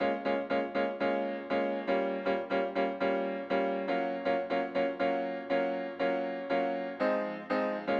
17 Piano PT1.wav